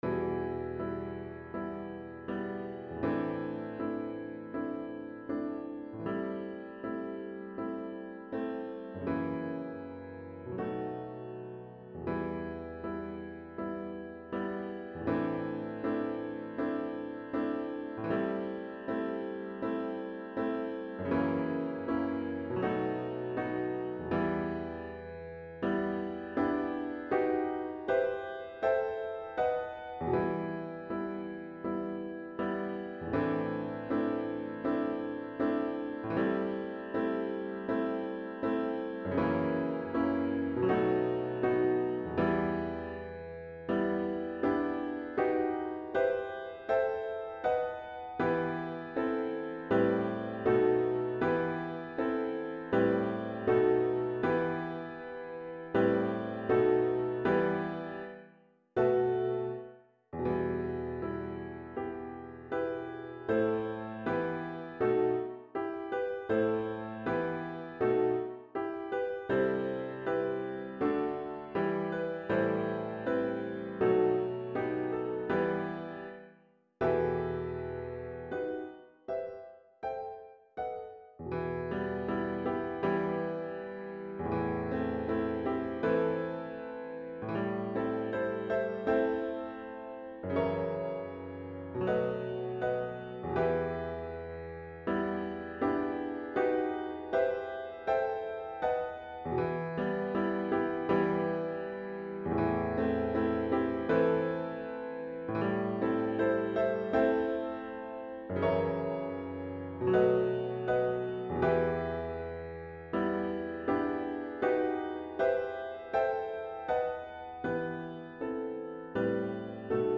Backing Track
Piano Accompaniment
Feeling-the-Heat-Piano-Accompniament.mp3